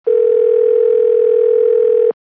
outgoing.wav